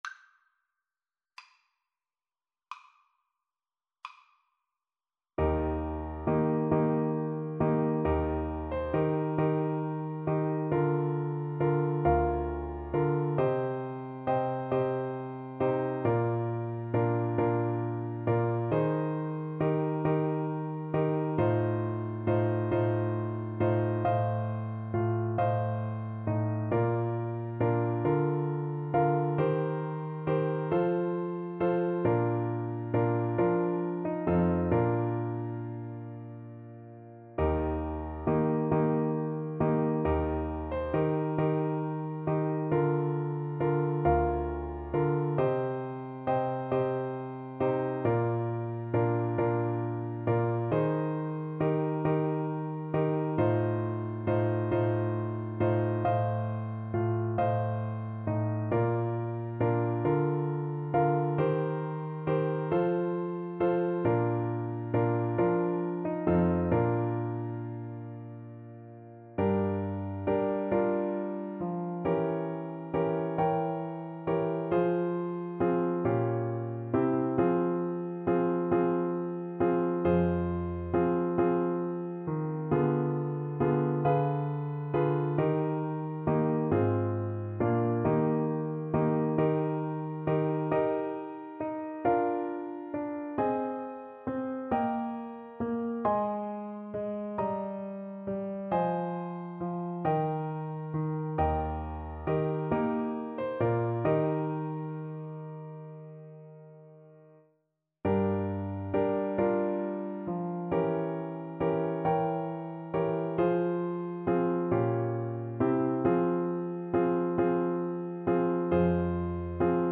Play (or use space bar on your keyboard) Pause Music Playalong - Piano Accompaniment Playalong Band Accompaniment not yet available reset tempo print settings full screen
12/8 (View more 12/8 Music)
II: Larghetto cantabile .=45
Eb major (Sounding Pitch) F major (Trumpet in Bb) (View more Eb major Music for Trumpet )
Classical (View more Classical Trumpet Music)